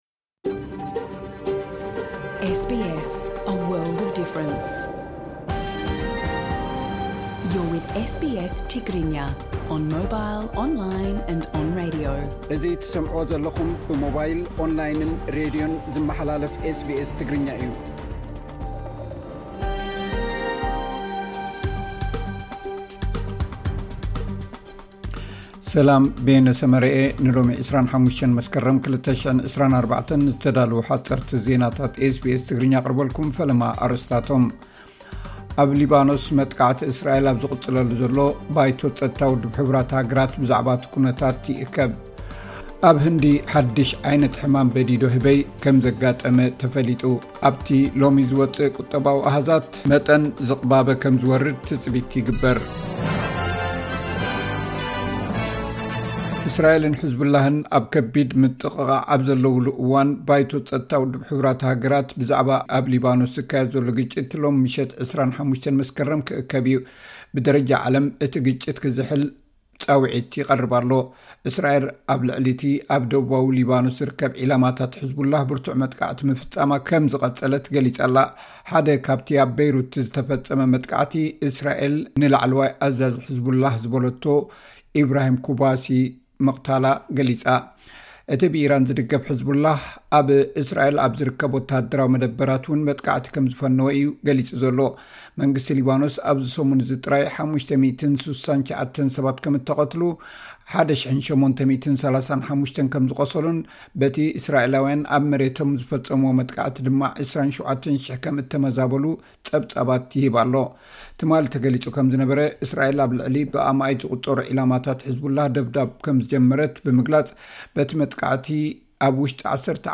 ሓጸርቲ ዜናታት ኤስ ቢ ኤስ ትግርኛ (25 መስከረም 2024)